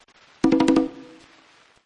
Tesla Lock Sound Drums – Congas 1
Congas sound
(This is a lofi preview version. The downloadable version will be in full quality)
JM_Tesla_Lock-Sound_Drums-Congas-1_Watermark.mp3